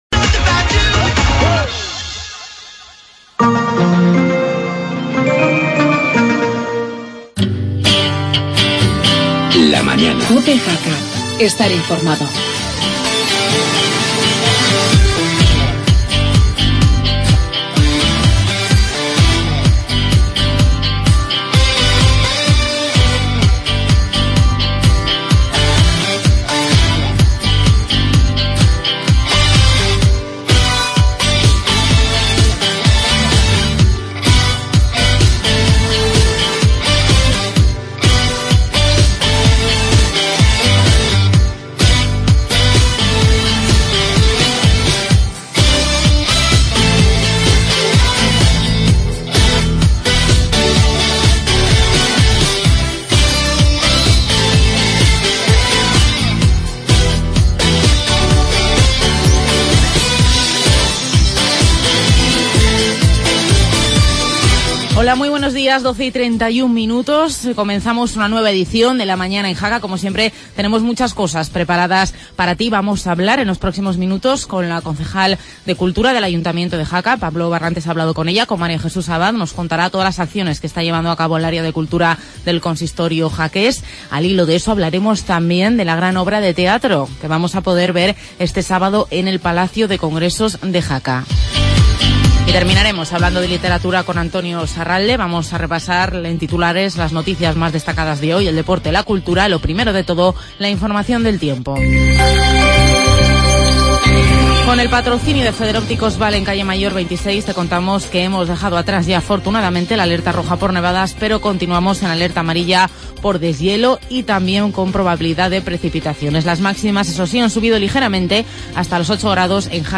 AUDIO: Actualidad y entrevista a la concejal de cultura María Jesús Abad.